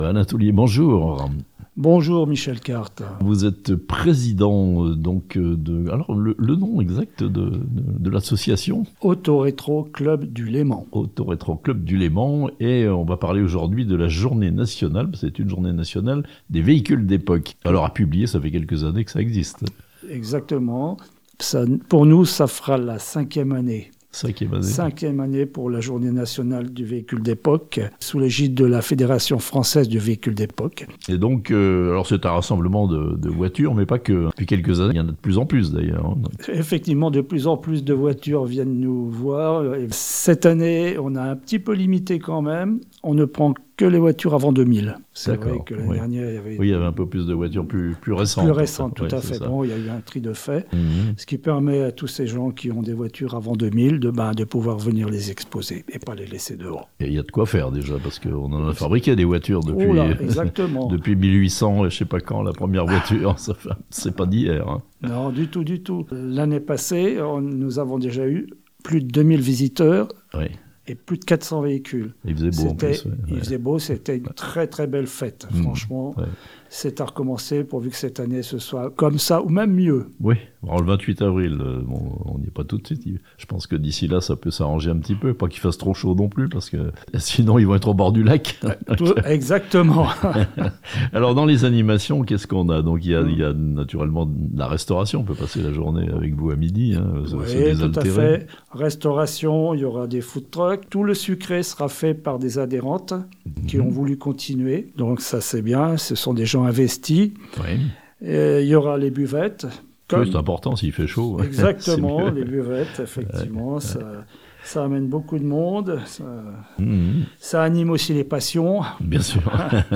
L'Auto Rétro Club du Léman rend hommage aux véhicules d'époque le dimanche 28 avril à Amphion (interview)